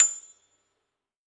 53s-pno28-A6.aif